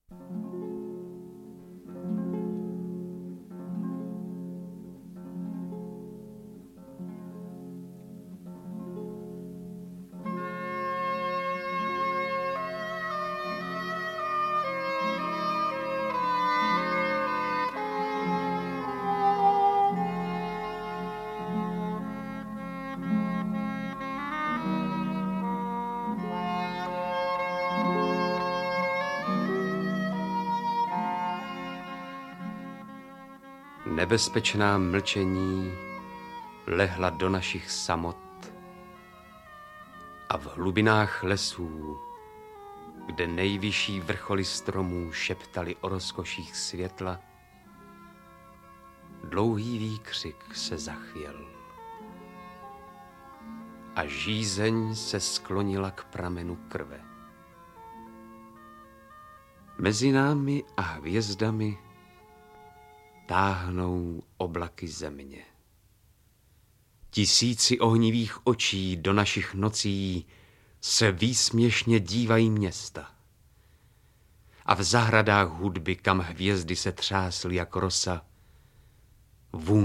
Interpreti:  Vladimír Brabec, Jan Kačer
Nyní titul s básněmi Šťastní, Až sedneš za můj stůl, Ztracení, Snad potom a Zem v interpretaci skvělých recitátorů Jana Kačera a Vladimíra Brabce vychází poprvé digitálně OTOKAR BŘEZINA, vlastním jménem Václav Ignác Jebavý (*13....
beletrie / poezie